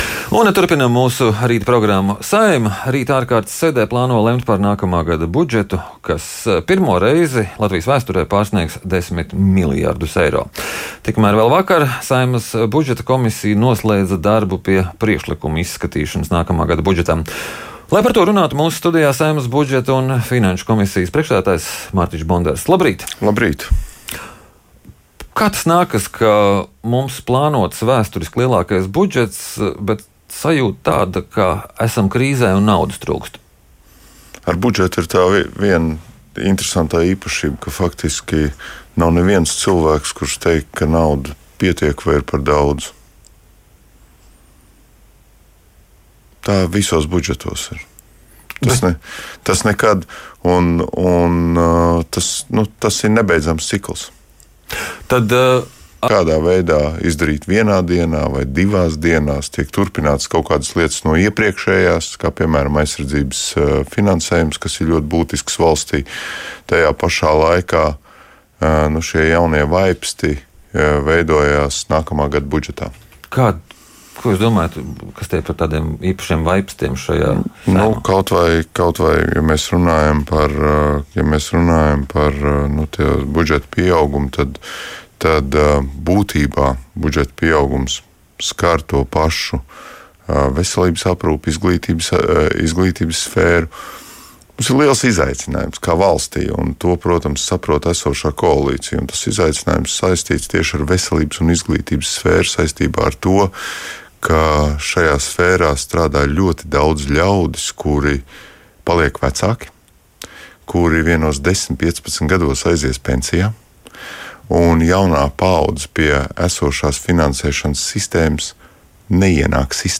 Nākamā gada valsts budžets ir kompromiss un pie tā jau ir strādāts kopš vasaras, tā intervijā Latvijas Radio atzina Saeimas budžeta un finanšu komisijas priekšsēdētājs Mārtiņš Bondars (Attīstībai/Par). Vakar Saeimas komisijā tika izskatīti visi iesniegtie priekšlikumi, no kuriem tika atbalstīti pozīcijas deputātu un valdības ierosinājumi. Diemžēl deputāti nav atraduši vairāk naudas mediķu atalgojuma palielinājumam.